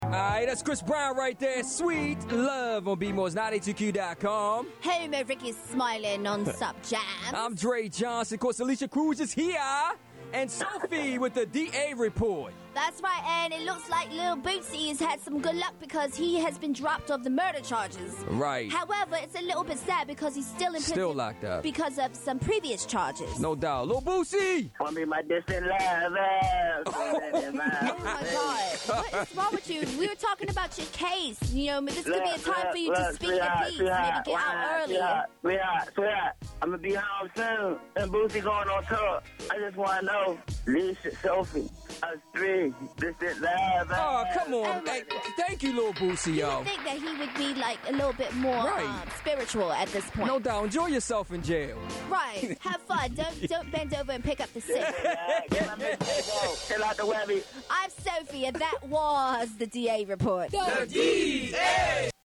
Lil Boosie Calls the D.A. From Prison
Click to hear Lil Boosie from jail!
click-to-hear-lil-boosie-from-jail.mp3